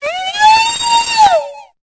Cri de Cocotine dans Pokémon Épée et Bouclier.